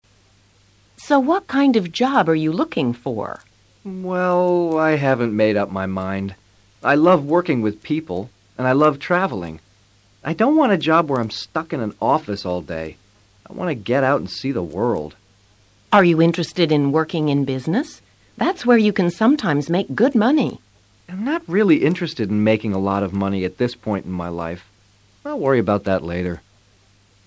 A continuación escucharás a tres entrevistadores.